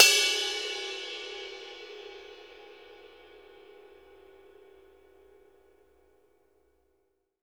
BELL      -L.wav